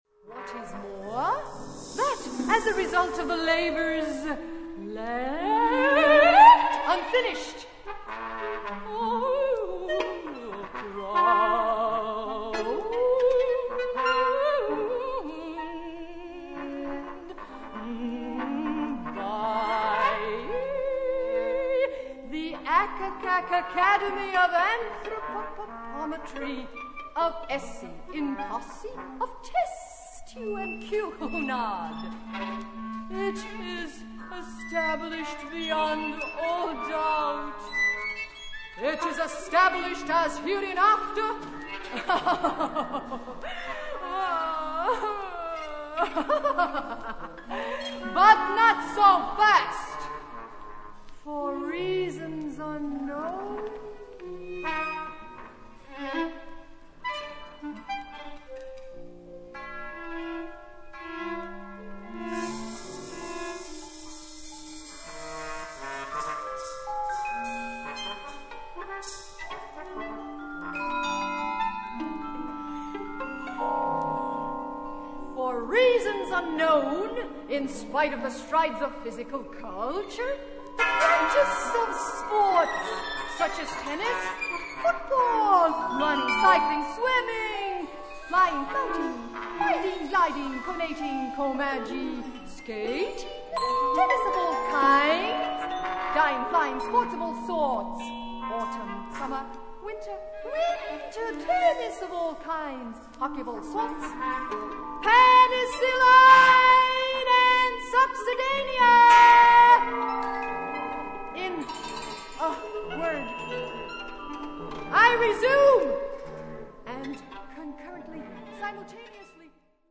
Für Sprechstimme Und Acht Instrumente